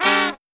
FALL HIT11-R.wav